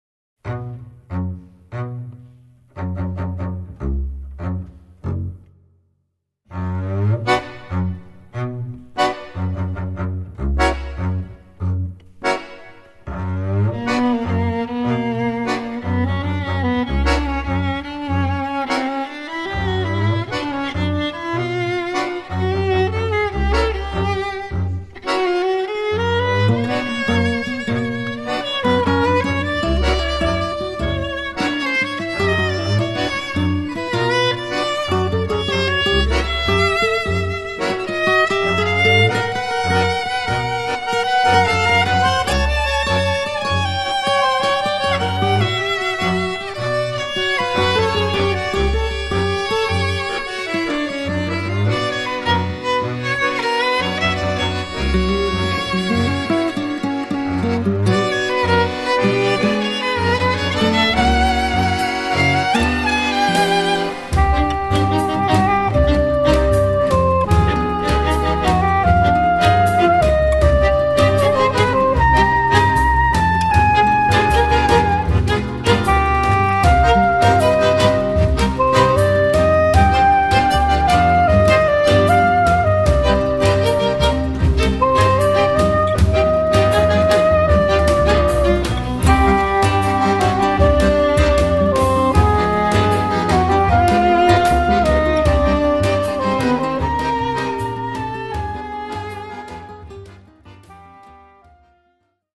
fisarmonica
sax soprano e clarinetto
viola
chitarra
contrabbasso
batteria
percussioni
jazz, musica mediterranea, popolare e tango